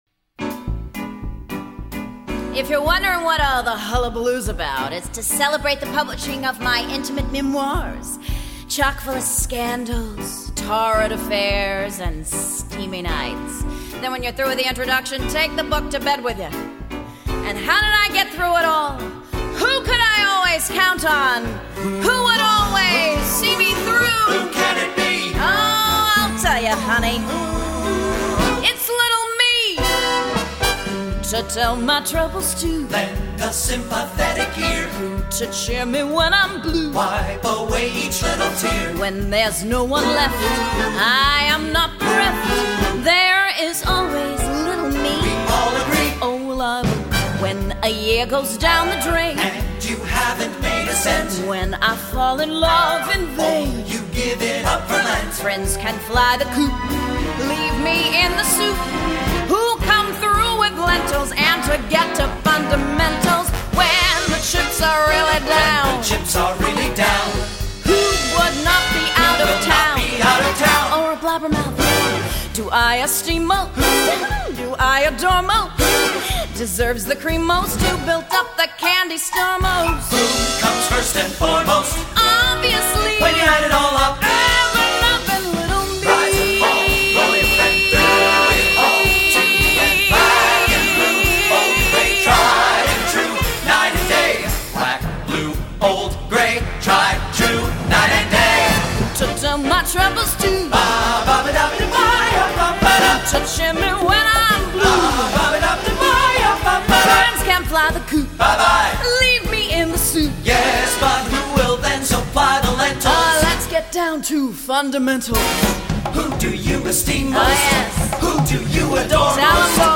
campier than others I’ve heard
Genre: Musical